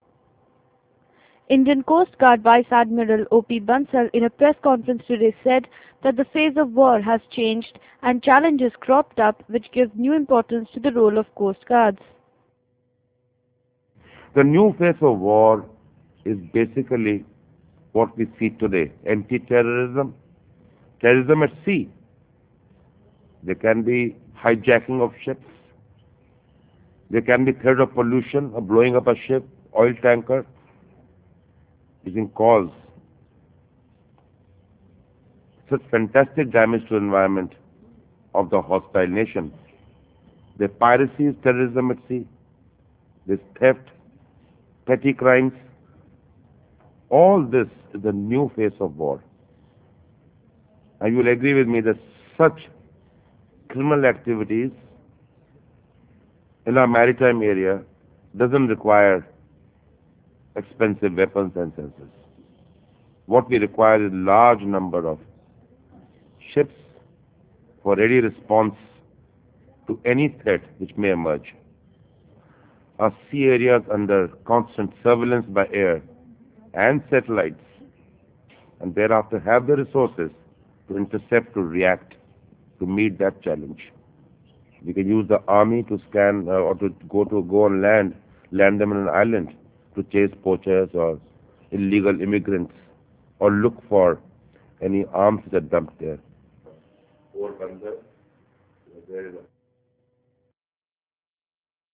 Indian Coastguard Chief Vice Admiral O.P. Bansal in a Press conference on Thursday said the phase of war had changed and challenges had cropped up, which gave importance to the role of Coastguards.